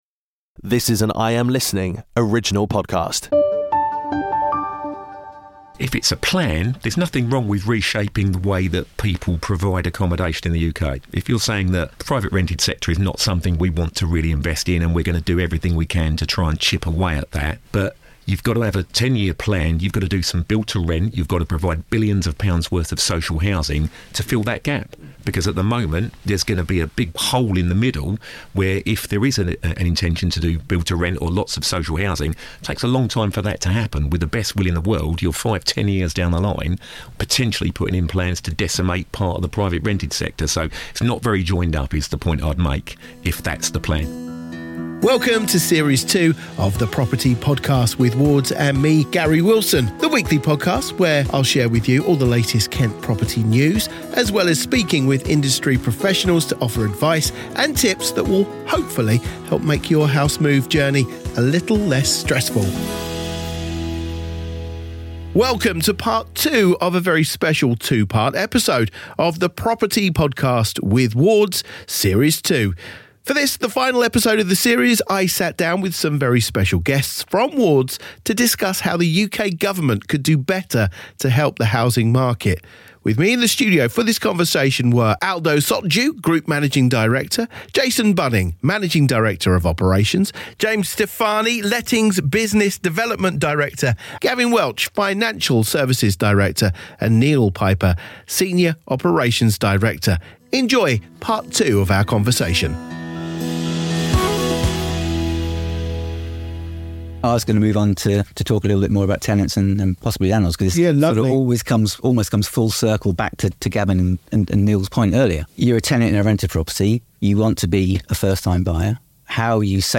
Enjoy the conversation!!